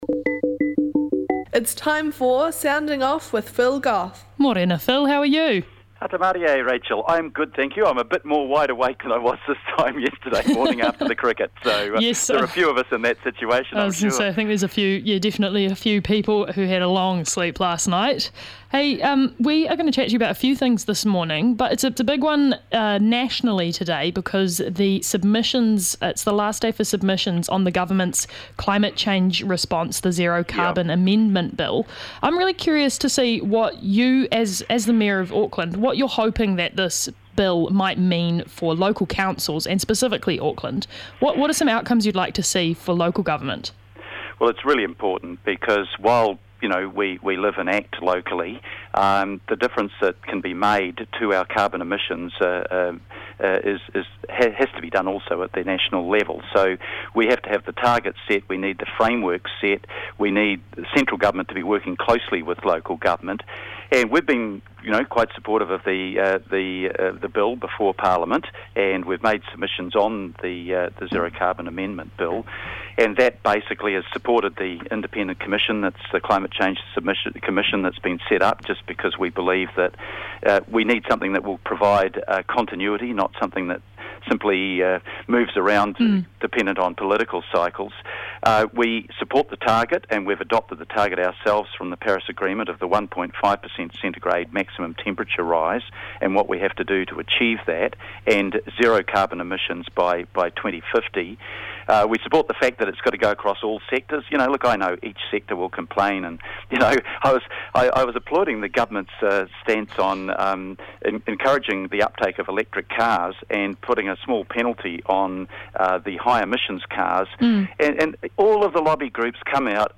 Our weekly catch up with the His Worship the Mayor of Auckland, the Honourable Phil Goff.